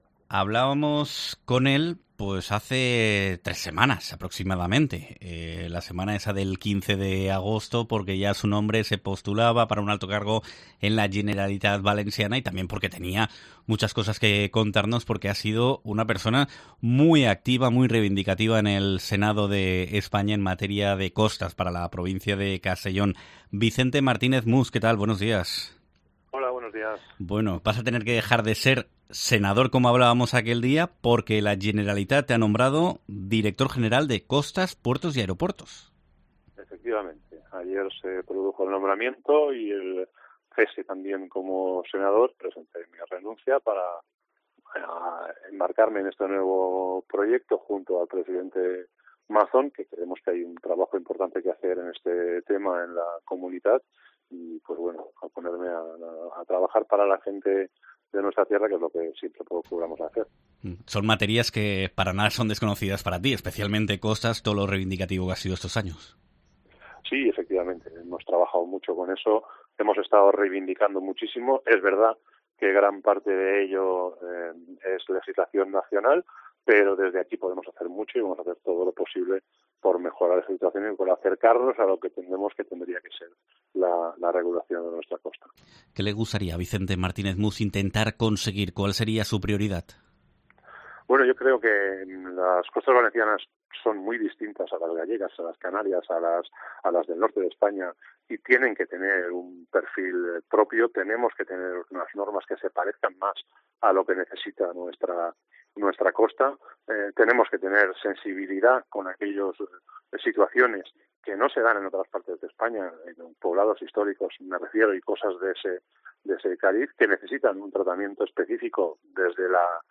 Un hombre que siempre ha tenido muy presente la mejora de nuestro territorio provincial en materia de costas y en sectores como el pesquero o aeronáutico, siempre ha ido cargado de propuestas para llevar a cabo un labor significativa y se ha acercado a los micrófonos de COPE Castellón para explicar sus próximos trabajos, proyectos y reivindicaciones.